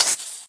Sound / Minecraft / mob / creeper1